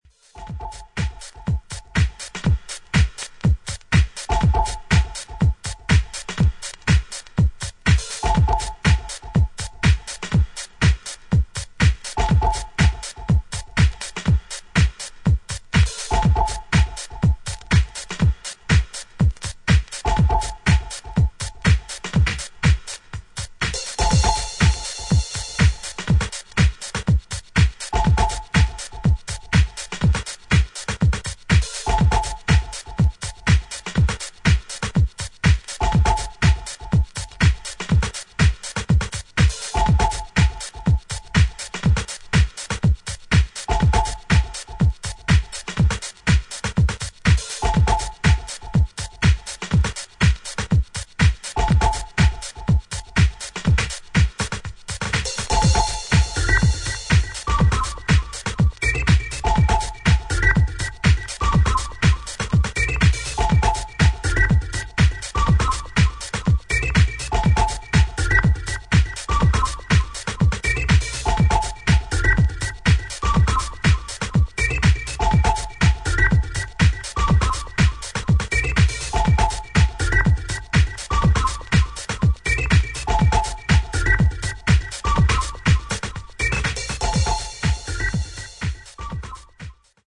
シンプルな編成ながらフロアのツボを押さえたお薦めトラック